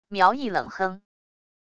苗毅冷哼wav音频
苗毅冷哼wav音频生成系统WAV Audio Player